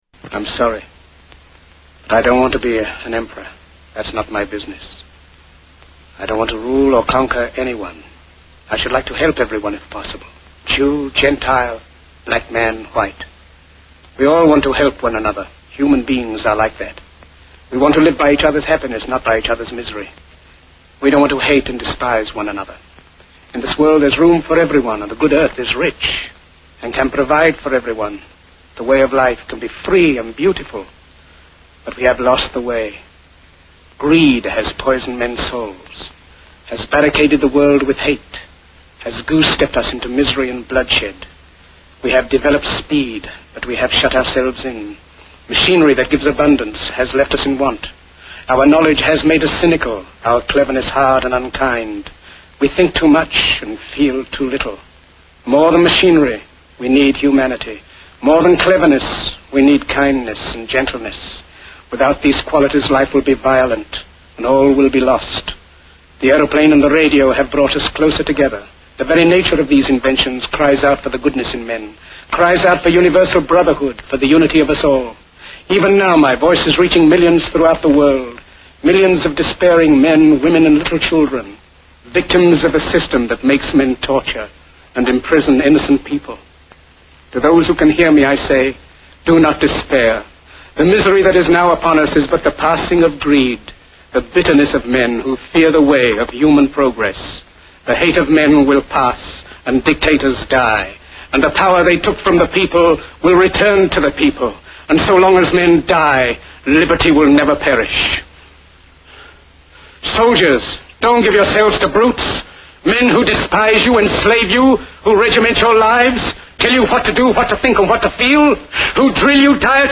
Tags: Greatest Movie Monologues Best Movie Monologues Movie Monologues Monologues Movie Monologue